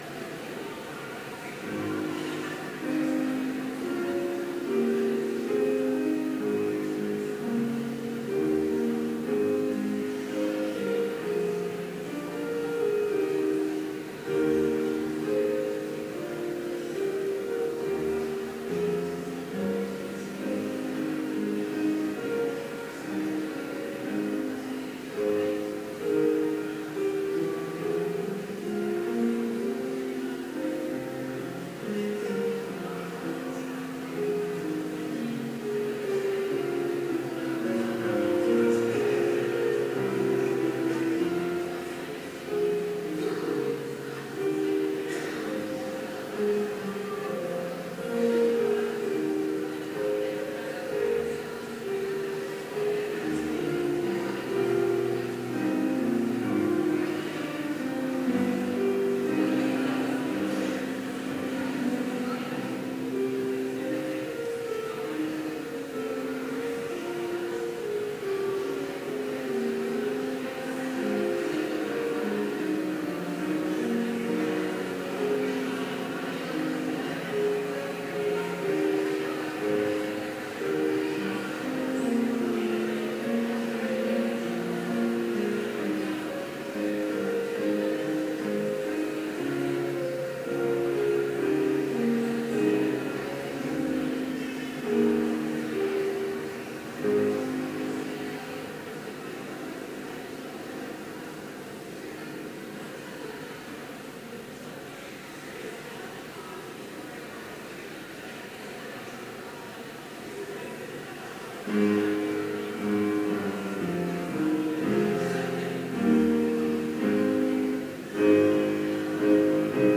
Complete service audio for Chapel - September 2, 2016